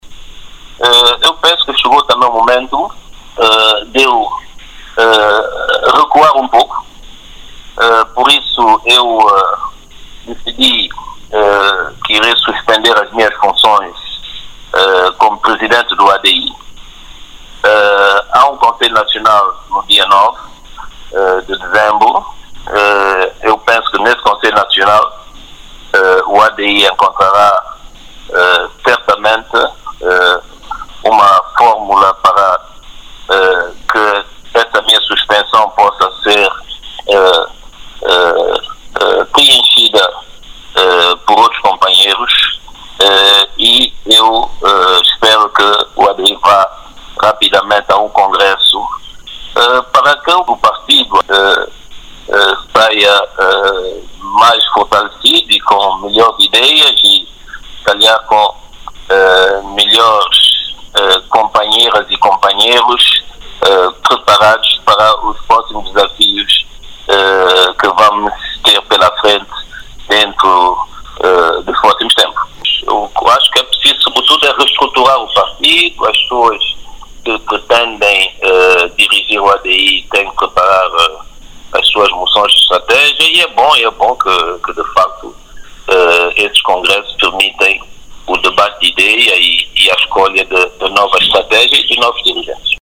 São-Tomé, 29 Nov. ( STP-Press) – O ainda primeiro-ministro são-tomense, Patrice Trovoada, decidiu suspender as suas funções de liderança do seu partido ADI,  vencedor das eleições legislativas de 07 de Outubro, anunciou hoje o próprio Trovoada numa entrevista à Radio Nacional.
Ouça a declaração de Patrice Trovoada